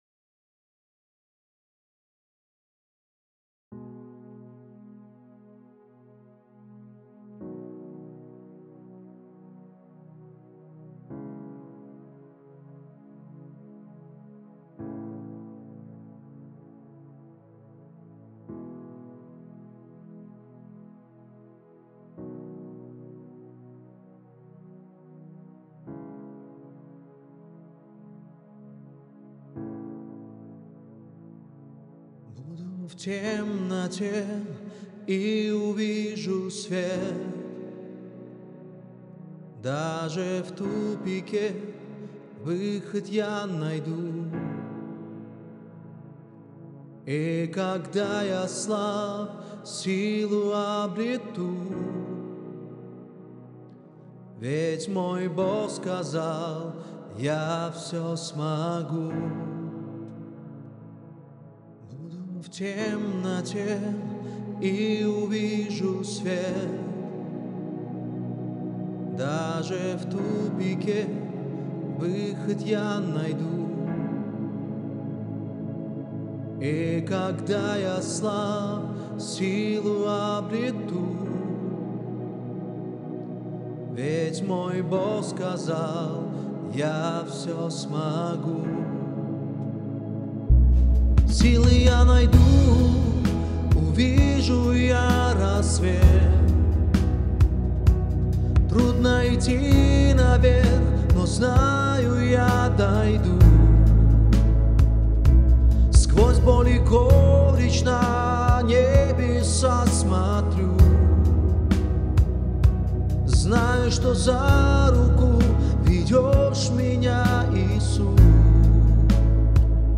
107 просмотров 226 прослушиваний 5 скачиваний BPM: 65